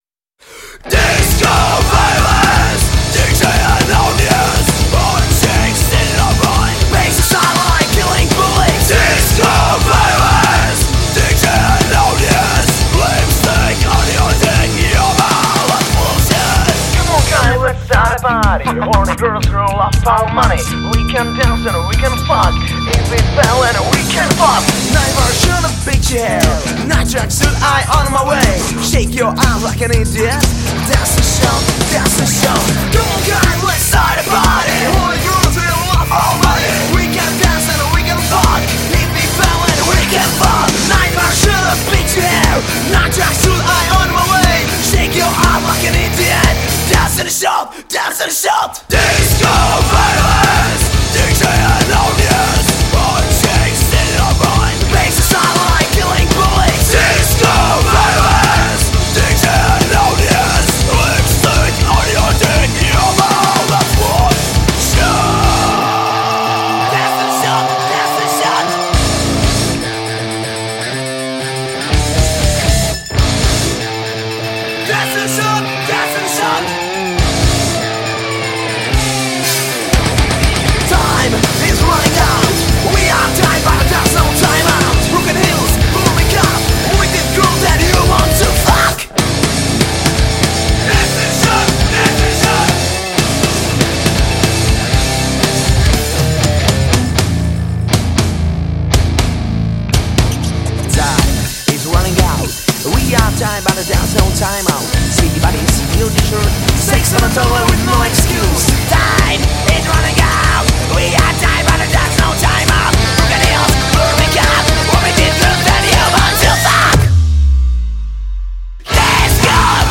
Žánr: Metal/HC
Dance metal s prvky elektronické hudby a moderního metalu.